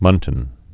(mŭntən)